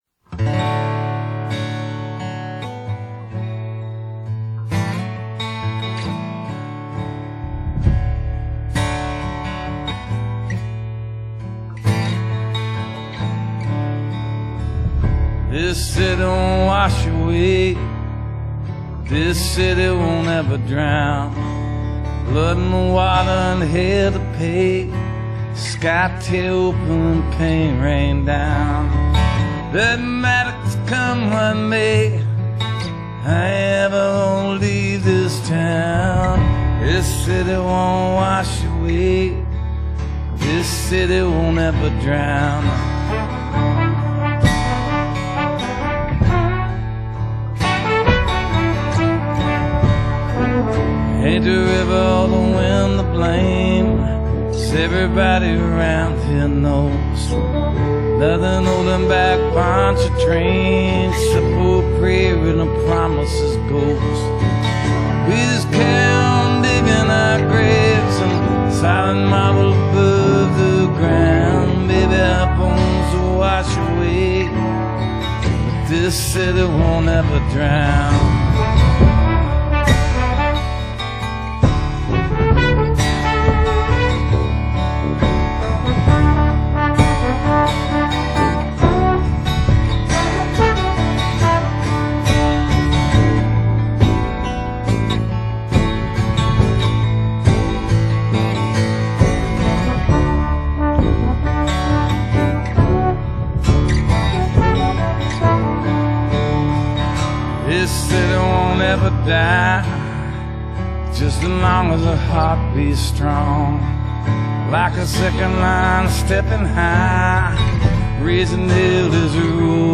Americana, Contemporary Country, Roots Rock, Alt-Country,
Alternative Rock, Contemporary Singer/Songwriter